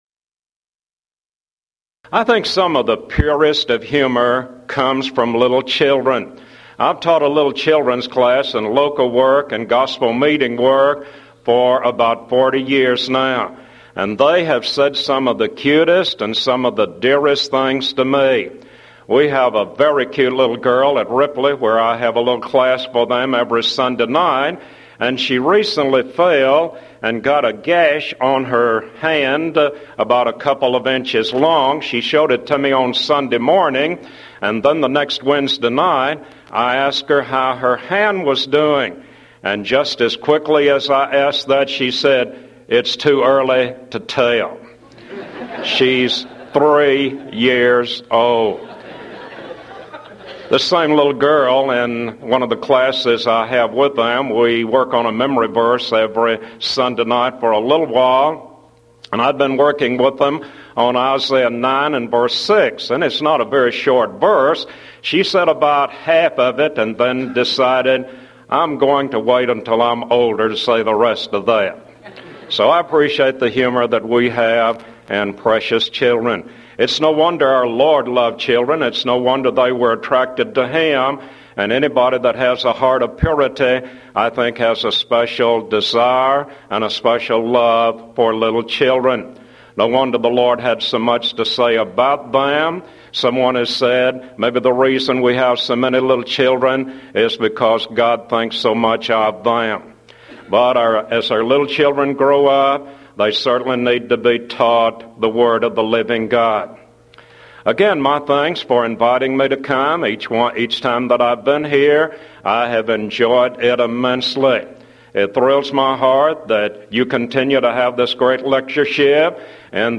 Event: 1994 Mid-West Lectures
lecture